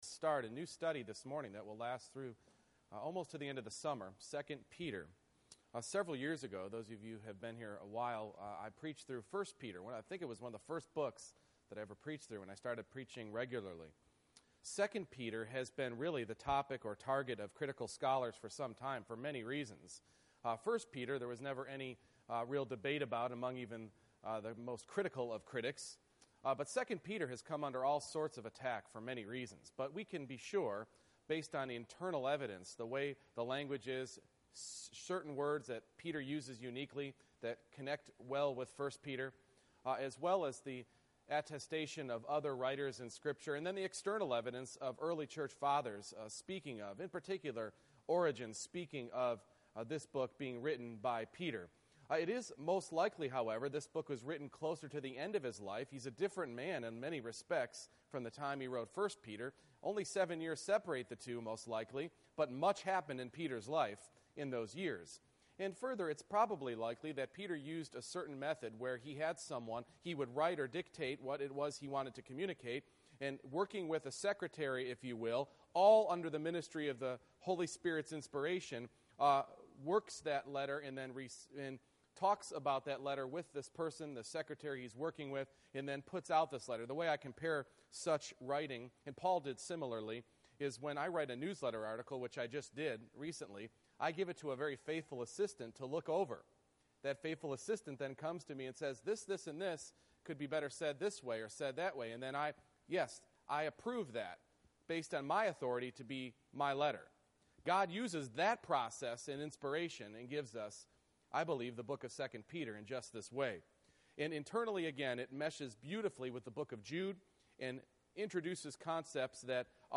2 Peter 1:1-4 Service Type: Morning Worship Growing in grace and knowledge go hand in hand.